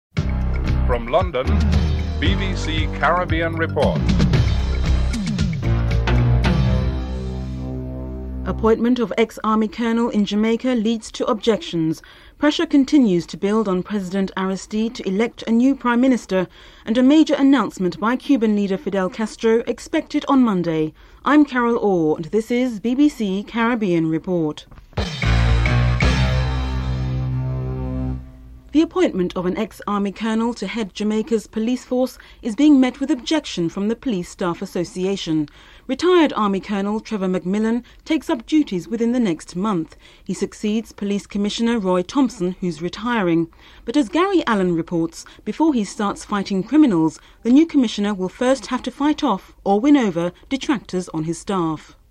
1. Headlines (00:00-00:33)